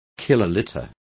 Complete with pronunciation of the translation of kilolitres.